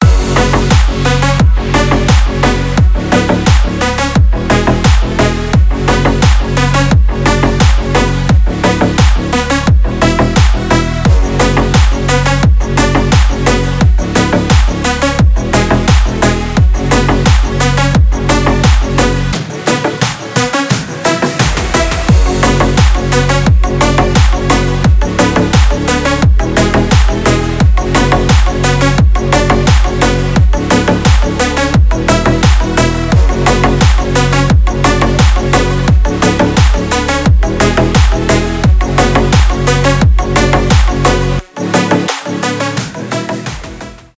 клубные , bass house , громкие
без слов